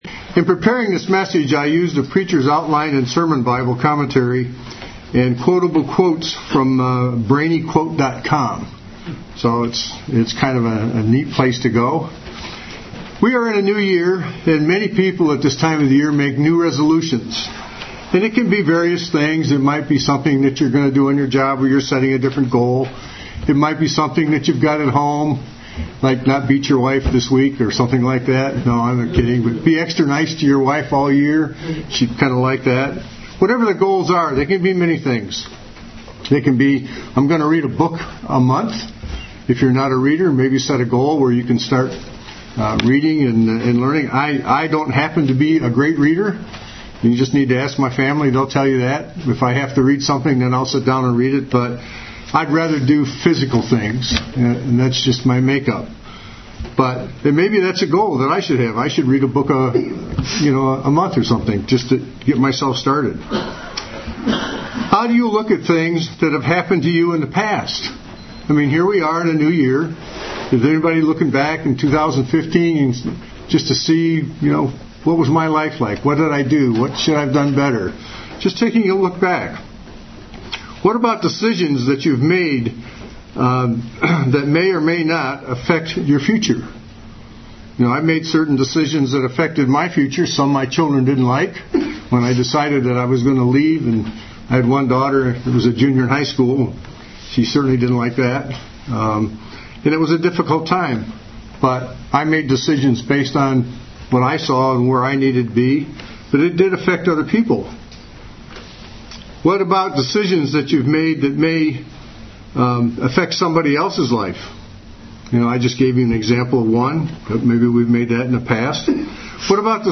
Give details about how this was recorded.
Given in Cincinnati North, OH